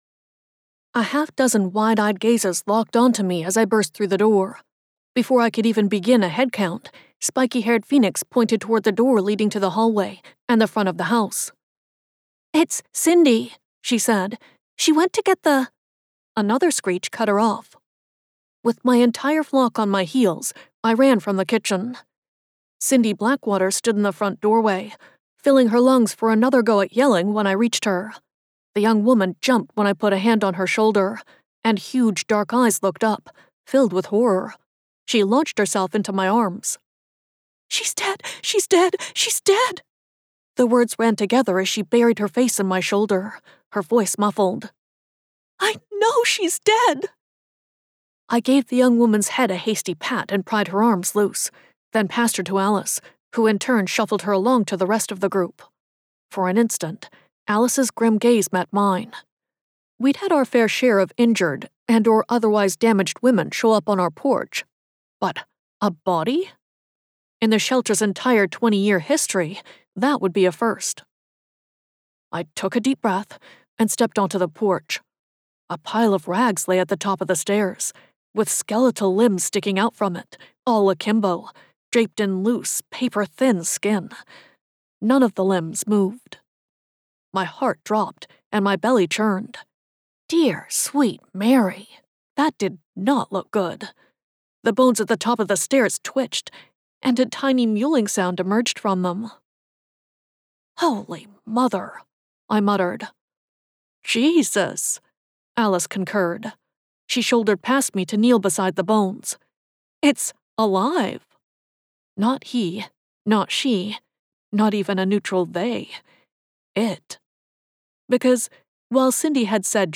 Related Audiobooks